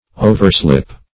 Search Result for " overslip" : The Collaborative International Dictionary of English v.0.48: Overslip \O`ver*slip"\, v. t. To slip or slide over; to pass easily or carelessly beyond; to omit; to neglect; as, to overslip time or opportunity.